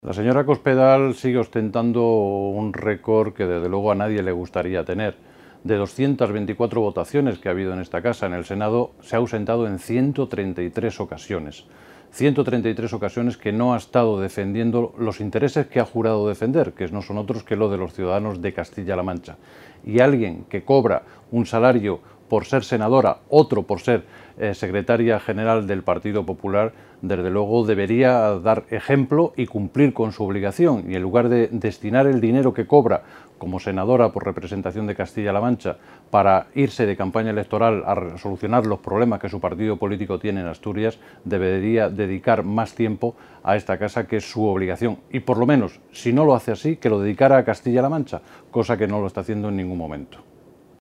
El senador socialista José Miguel Camacho ha criticado hoy que la dirigente del PP y senadora en representación de las Cortes de C-LM, María Dolores de Cospedal, haya empezado el año en la Cámara Alta “como lo terminó y como viene siendo habitual en ella: haciendo novillos”.
Cortes de audio de la rueda de prensa